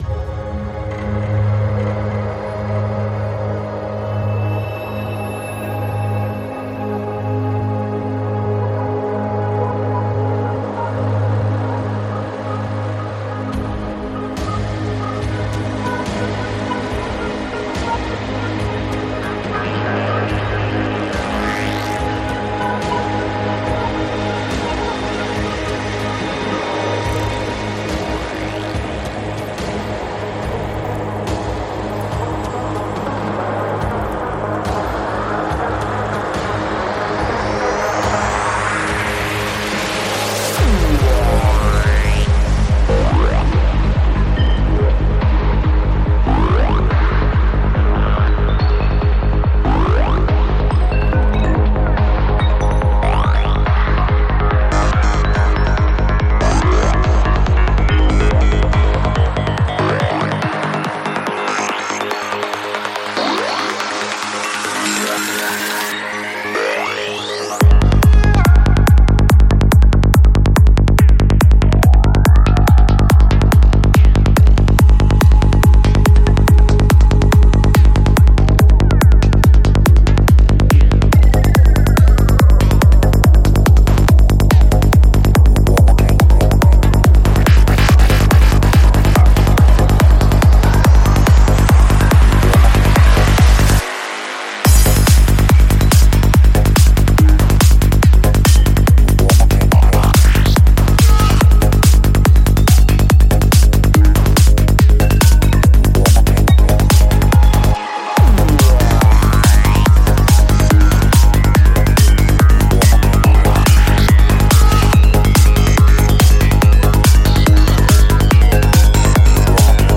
Жанр: Electro
Альбом: Psy-Trance